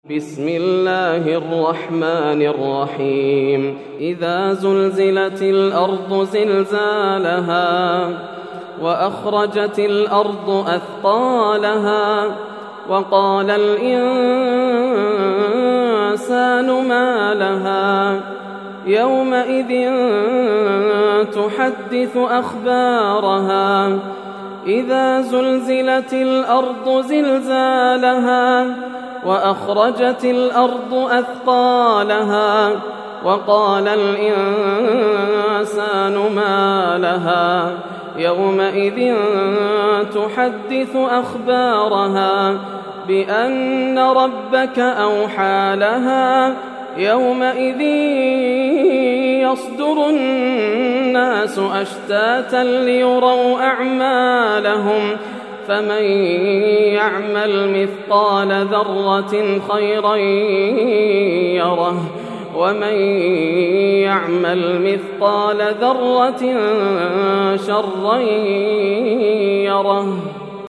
سورة الزلزلة > السور المكتملة > رمضان 1431هـ > التراويح - تلاوات ياسر الدوسري